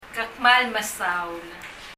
１．　フォーマルな表現　　　Ke kmal mesaul　　　[kɛ(ə) kməl mɛsaul]
発音